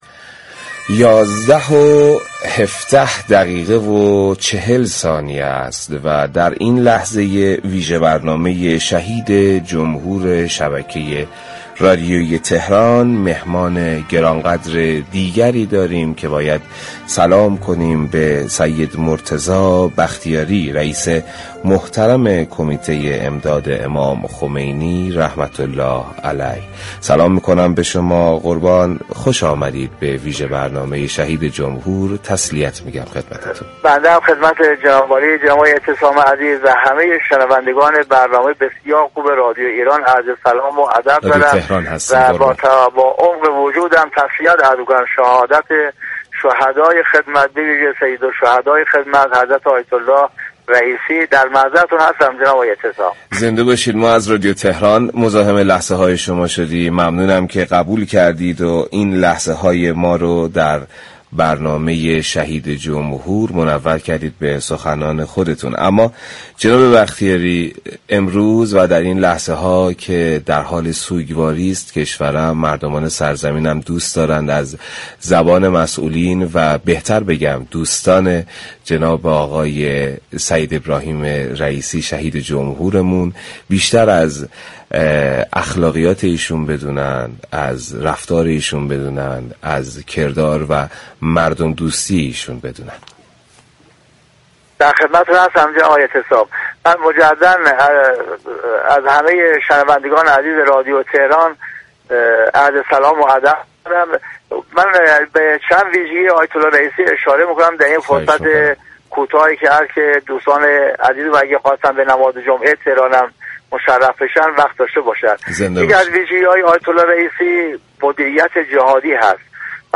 در گفتگو با برنامه «شهید جمهور» كه به مناسبت شهادت شهدای خدمت از رادیو تهران پخش می‌شود